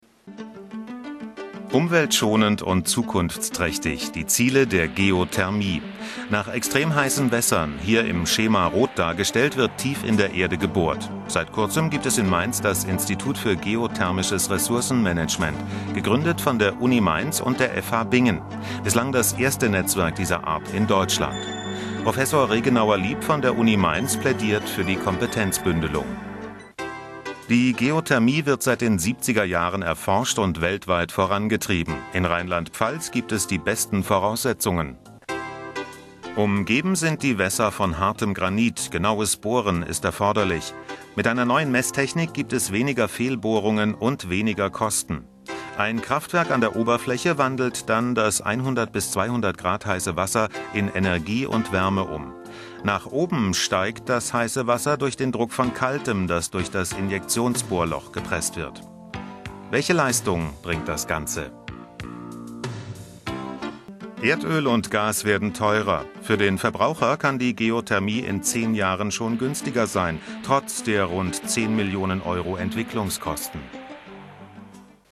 Sprecher deutsch.
Sprechprobe: Industrie (Muttersprache):
german voice over artist